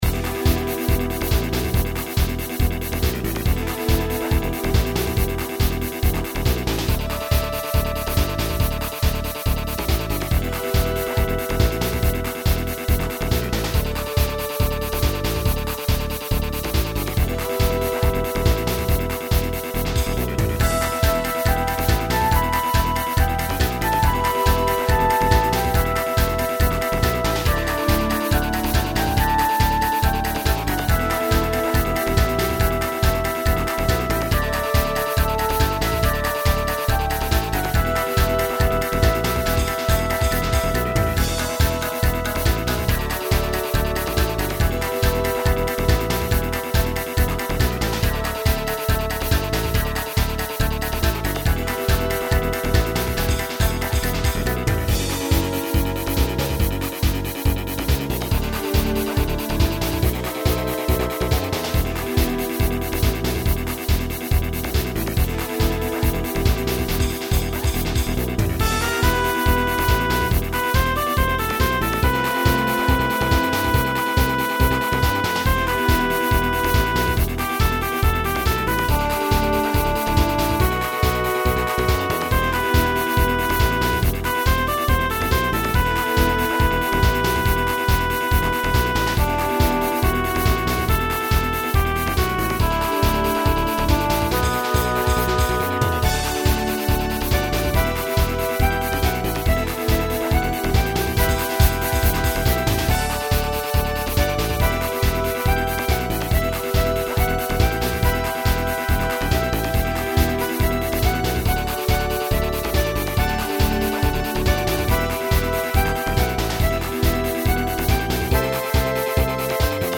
POP MUSIC